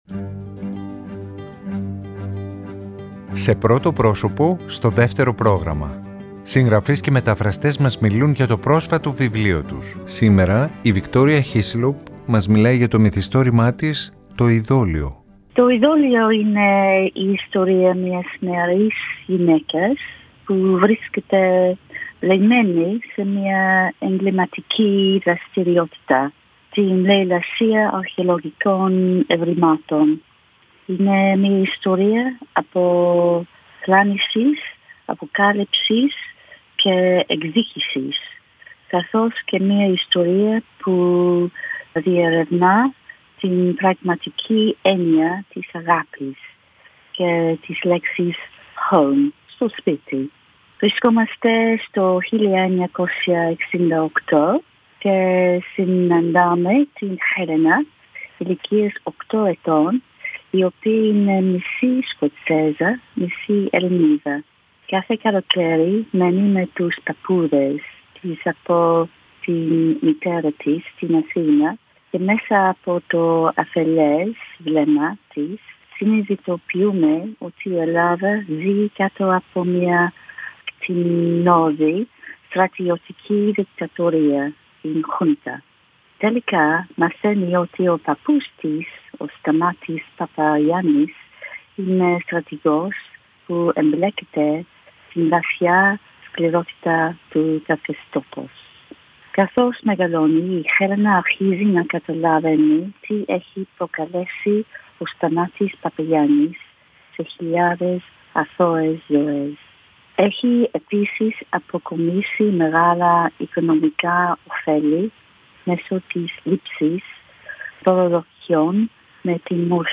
Σήμερα Τρίτη η Victoria Hislop μας μιλάει για το μυθιστόρημα της “Το ειδώλιο”.